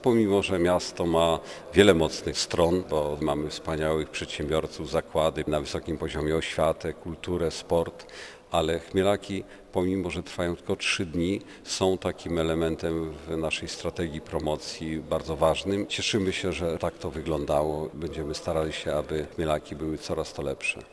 Andrzej Jakubiec potwierdza, że "Chmielaki Krasnostawskie" od lat pozostają znakomitą formą promocji miasta: